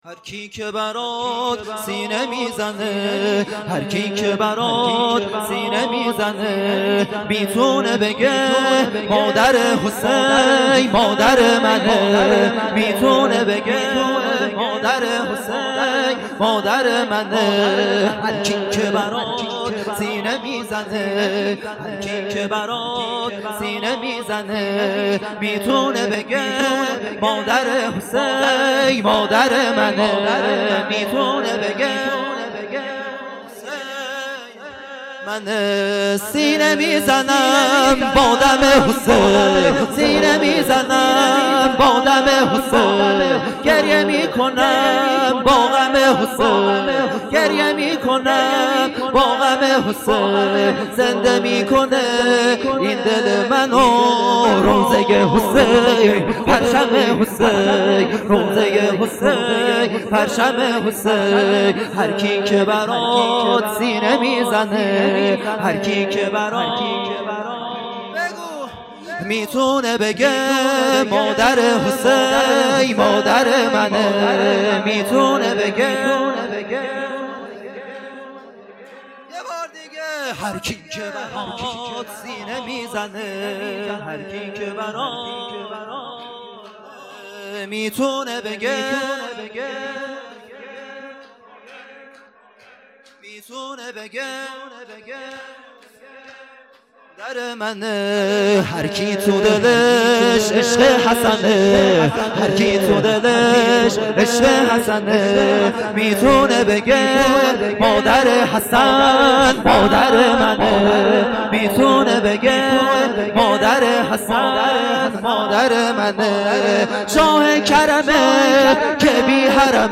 خیمه گاه - هیئت بچه های فاطمه (س) - شور | هرکی که برات سینه میزنه | 4 آذر 1400
جلسۀ هفتگی مشترک